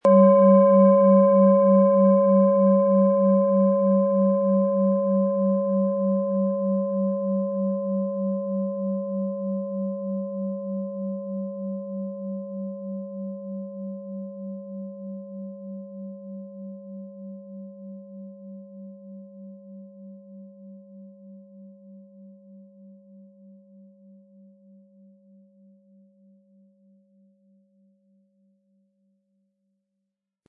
Planetenschale® Erde Dich & Vitalisieren und gestärkt werden mit Tageston, Ø 15,7 cm, 400-500 Gramm inkl. Klöppel
Um den Original-Klang genau dieser Schale zu hören, lassen Sie bitte den hinterlegten Sound abspielen.
Sanftes Anspielen mit dem gratis Klöppel zaubert aus Ihrer Schale berührende Klänge.
SchalenformBihar
MaterialBronze